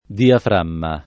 diaframma [ diafr # mma ]